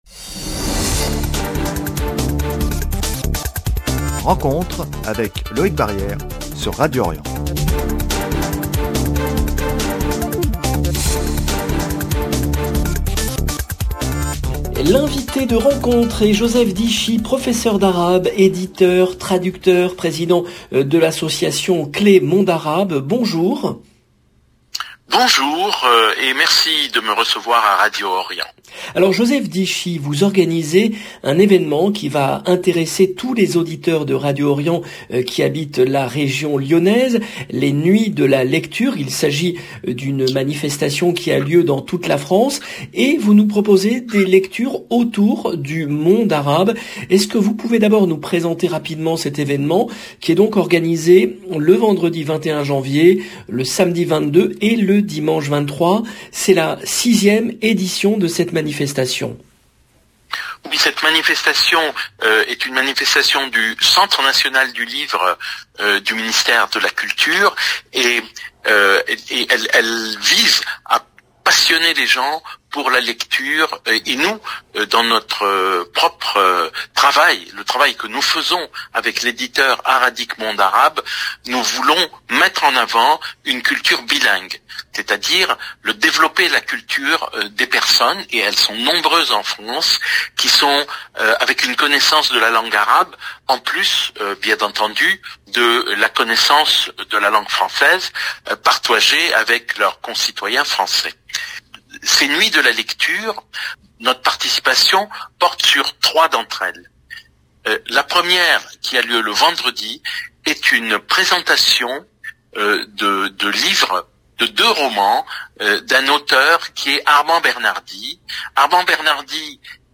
RENCONTRE, jeudi 20 janvier 2022